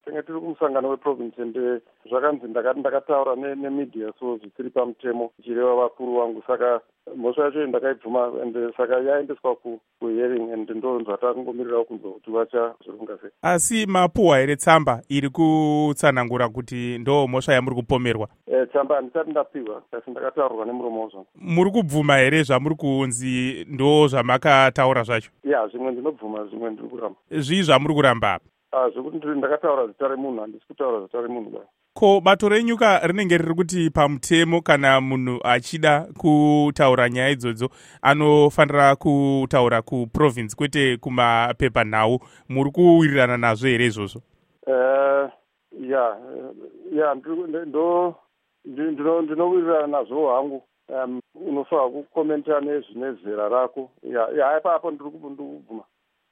Hurukuro naVaIan Kay